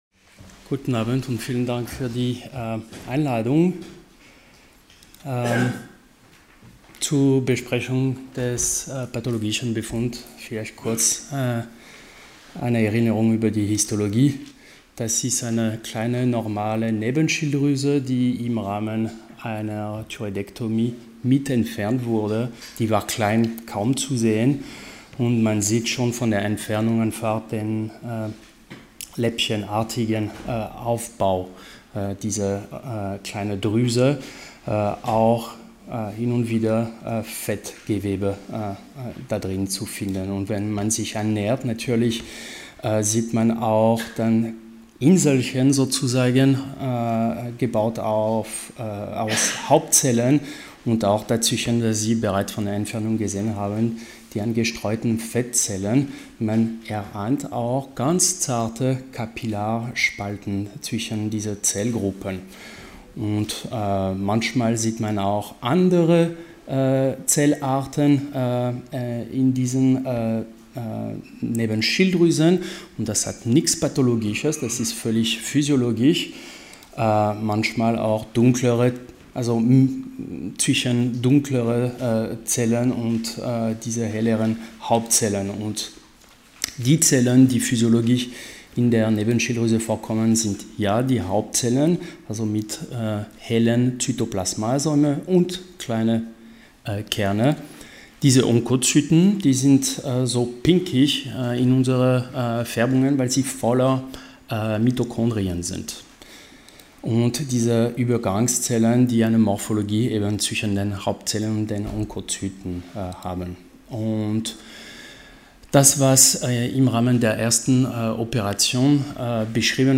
Sie haben den Vortrag noch nicht angesehen oder den Test negativ beendet.
Hybridveranstaltung